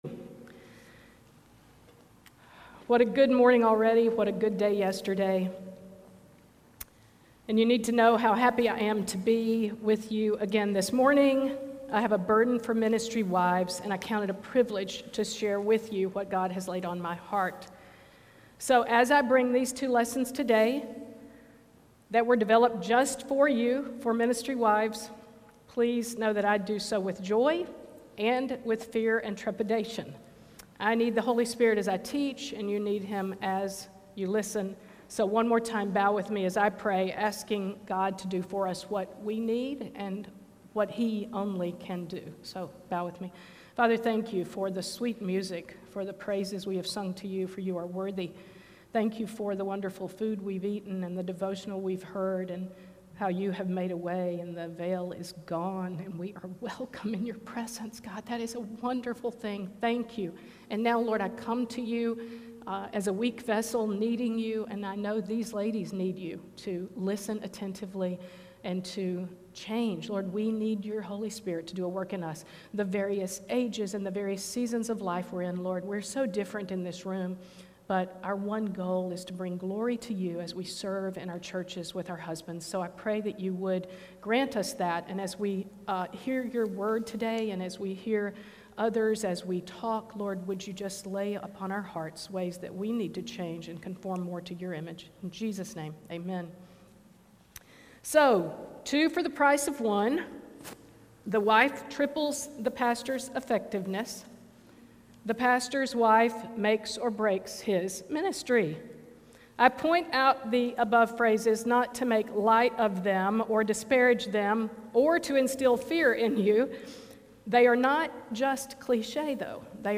Audio recorded at Feed My Sheep for Pastors Wives Conference 2021.